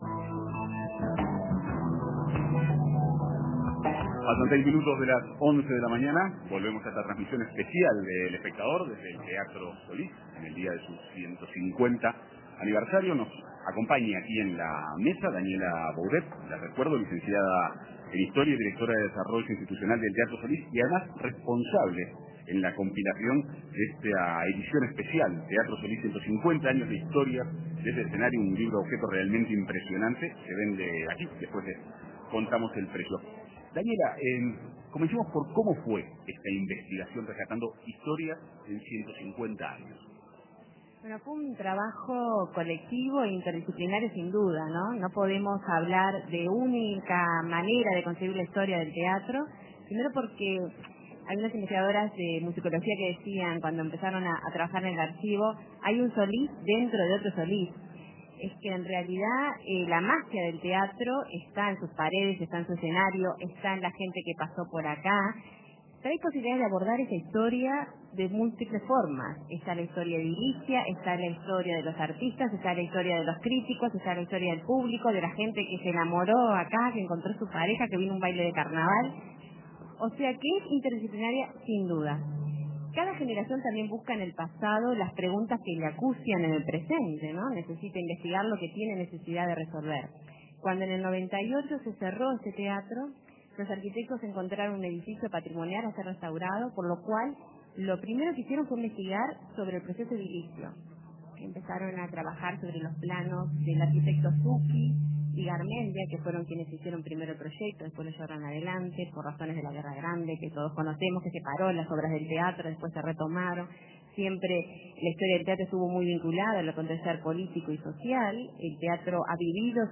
Entrevista con la coordinadora del libro